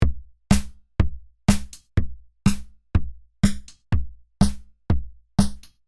Попробовал сделать баунс ударных конга. Действительно есть рассинхрон. Первый такт еще держит, затем начинает уплывать. Это наблюдается, если использовать друм секвенсор ризона.